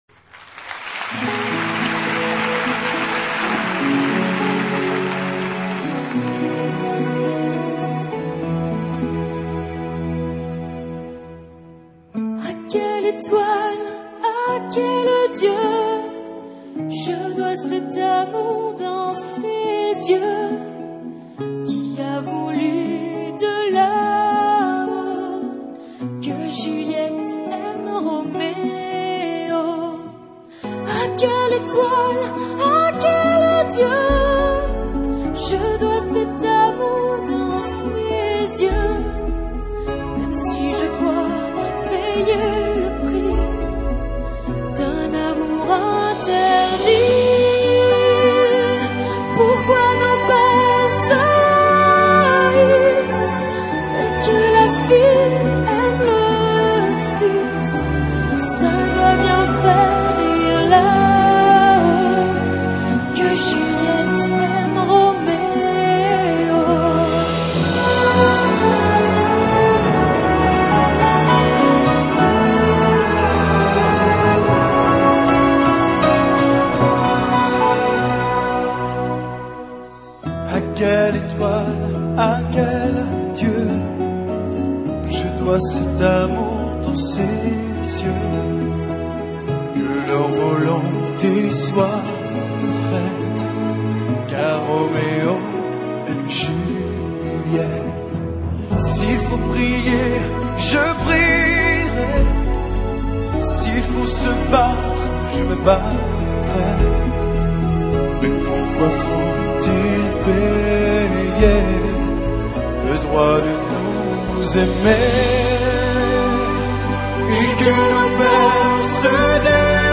這首曲子看曲名大概猜得出來，是茱莉葉與羅密歐分別在陽台上、下對唱的歌。